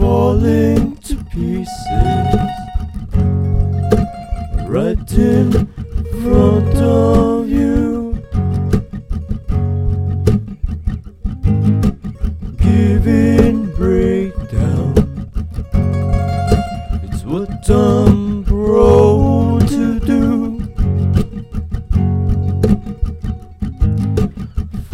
I made one minor adjustment to the way the riff fluctuated and came up with some words.
fly-early-loop.mp3